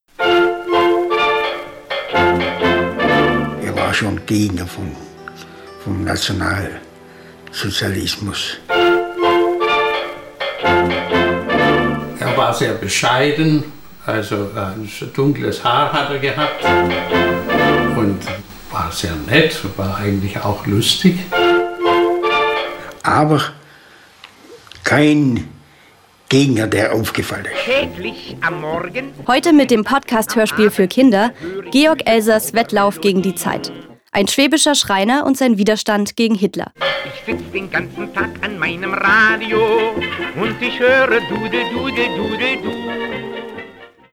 Georg Elsers Wettlauf gegen die Zeit. Ein schwäbischer Schreiner und sein Widerstand gegen Hitler – ein Kinderhörspiel (503)
Bei den Aufnahmen wurden sie von professionellen Sprecher*innen unterstützt.